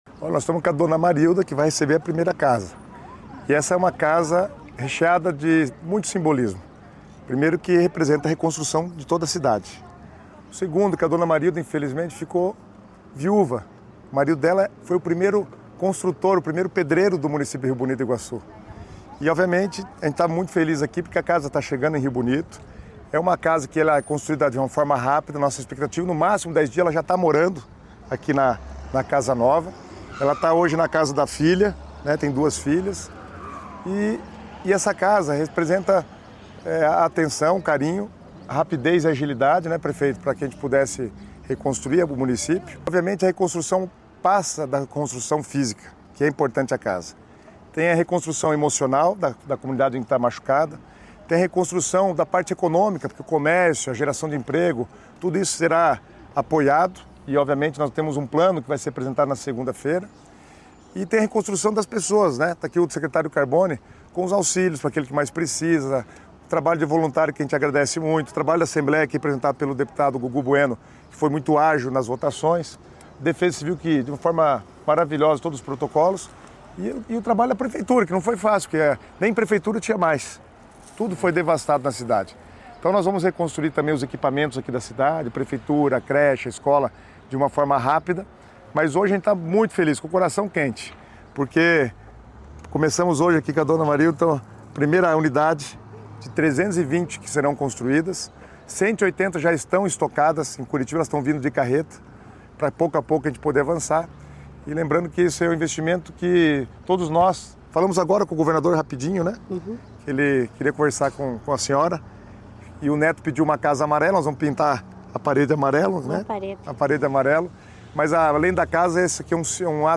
Sonora do secretário das Cidades, Guto Silva, sobre o envio de casas pré-fabricadas para moradores atingidos por tornado em Rio Bonito do Iguaçu